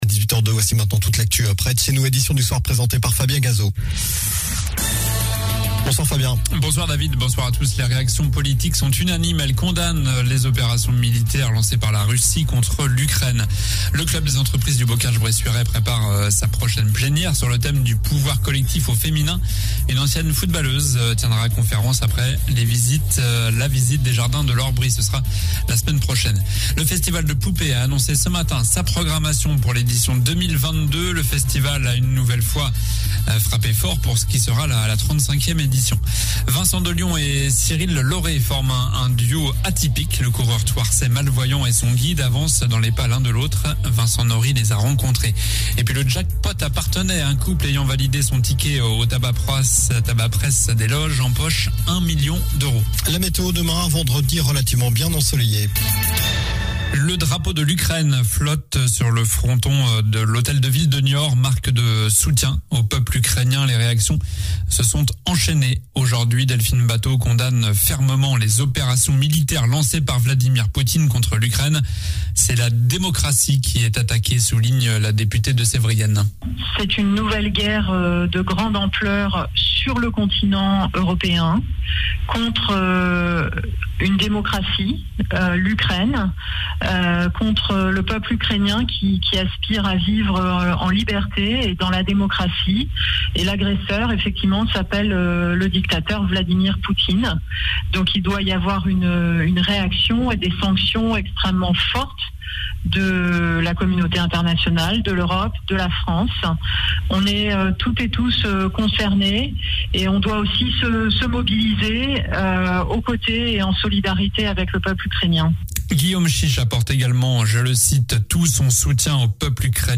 Journal du jeudi 24 février (soir)